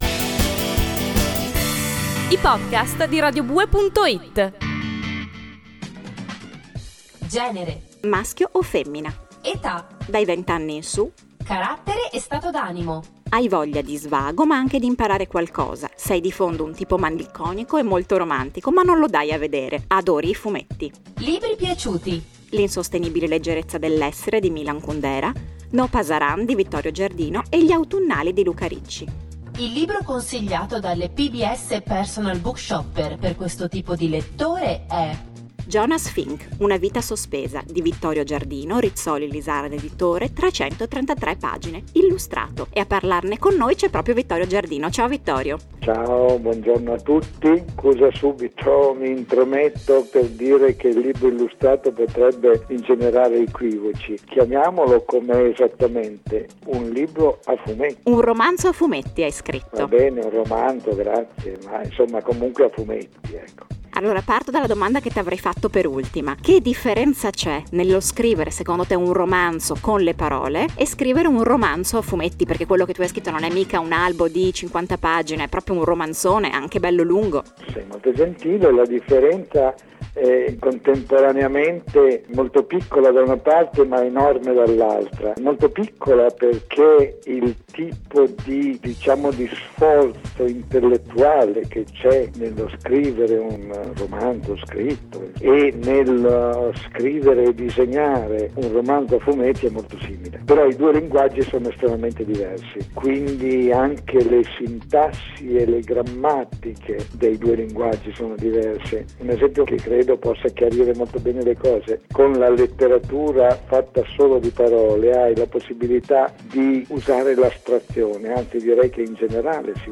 Jonas Fink, intervista a Vittorio Giardino